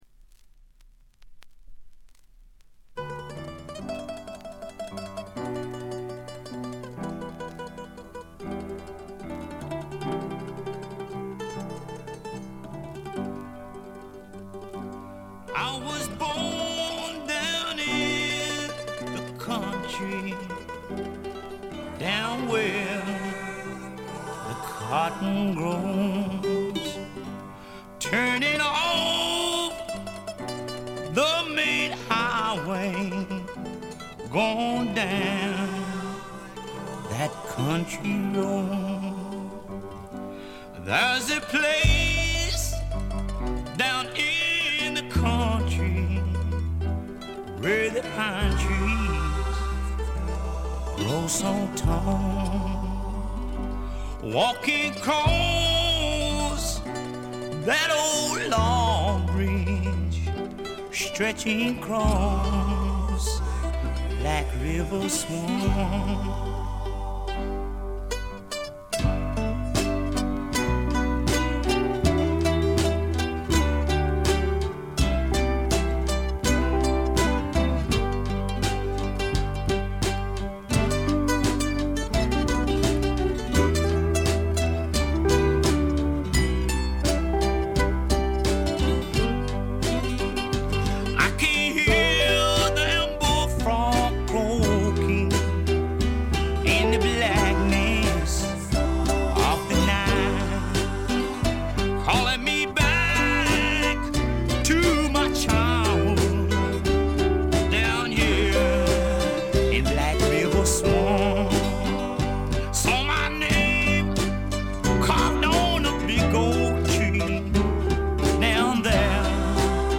最小限のバック編成と搾り出すようなヴォーカルが織り成す、ねばつくような蒸し暑いサウンド。
試聴曲は現品からの取り込み音源です。